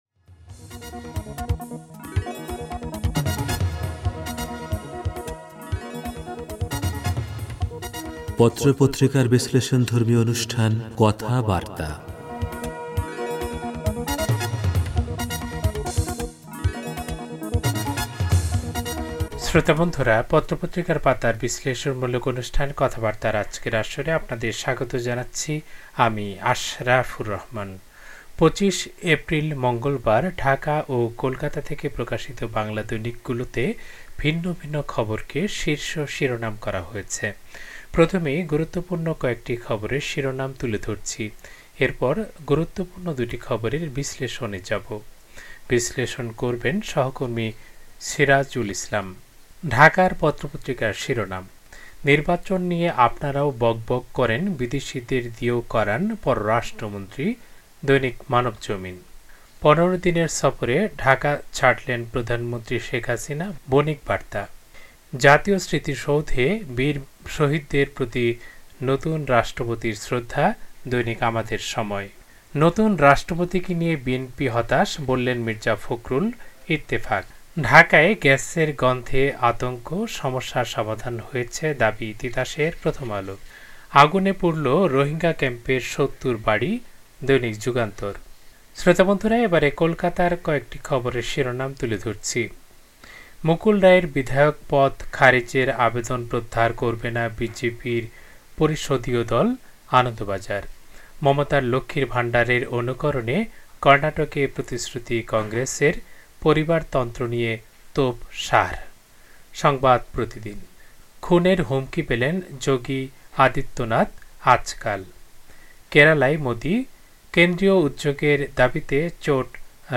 পত্রপত্রিকার পাতার অনুষ্ঠান